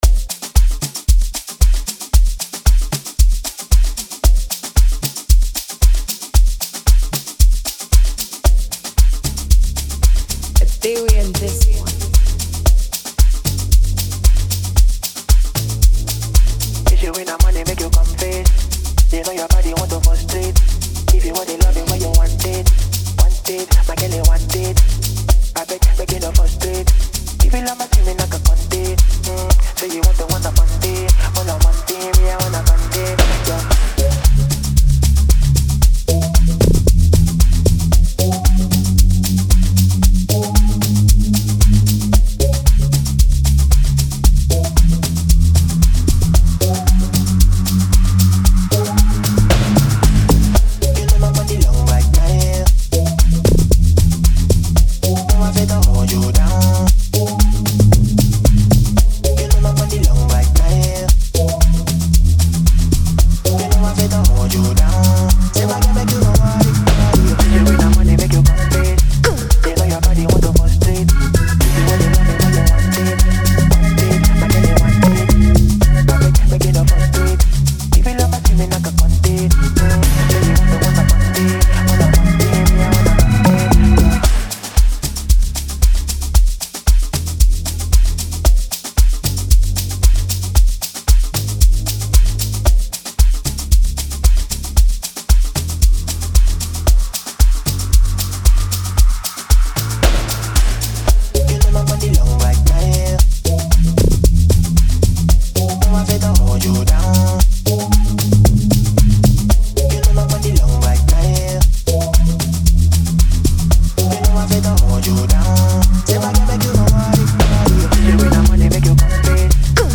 Free beat
open verse beat